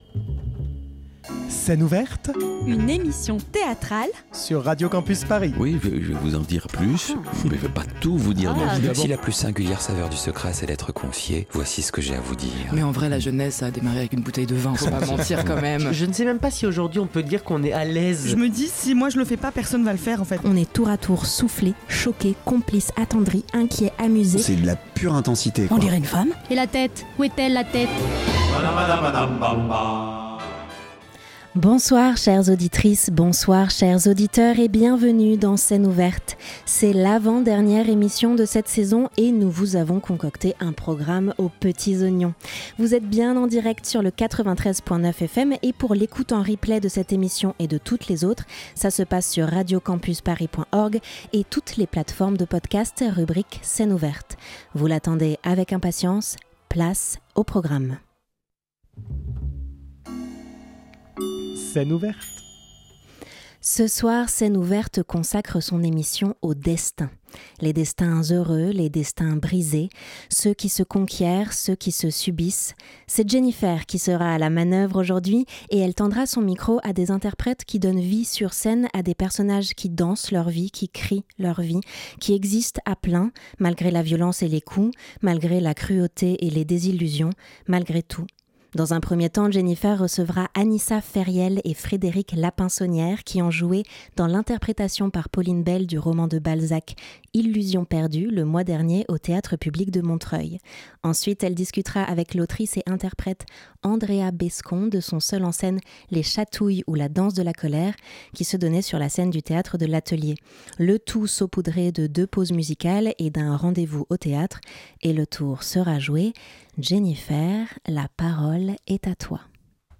Magazine Culture